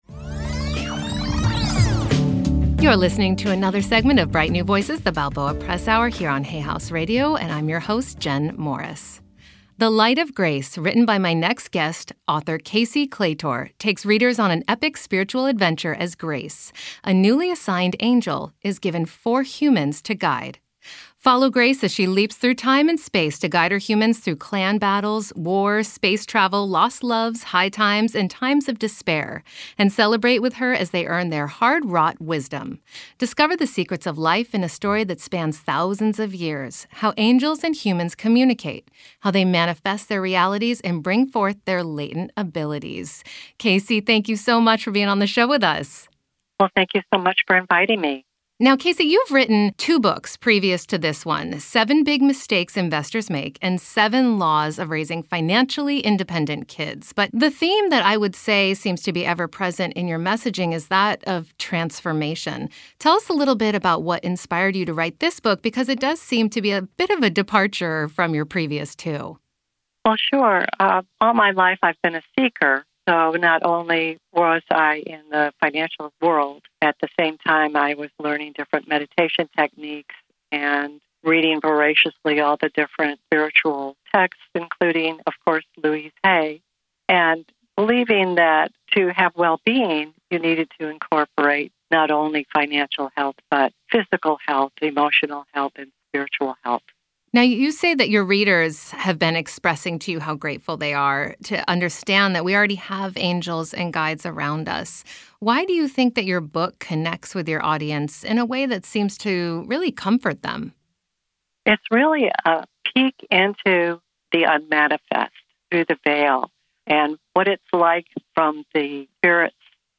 The sound quality isn’t the best, but it is easily understandable so I am posting it.